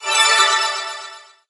get_pickup_05.ogg